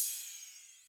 MB Open Hat (1).wav